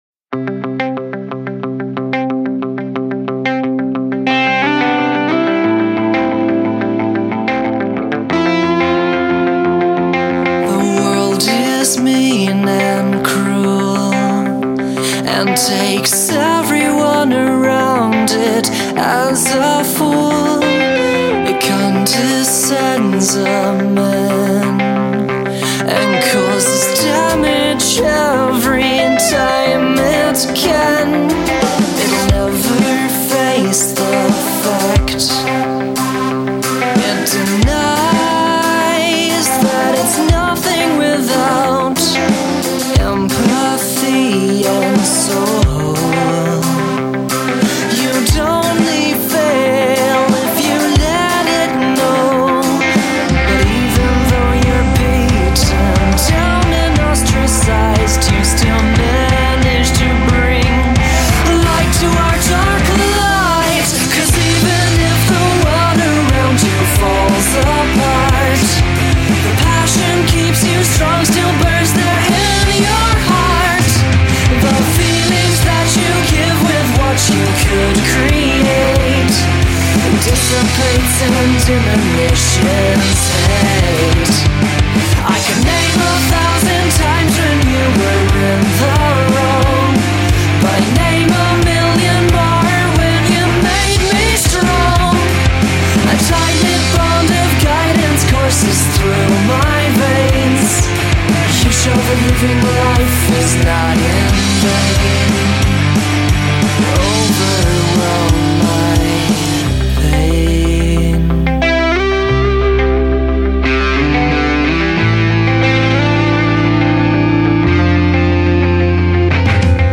I played the instruments and recorded it all.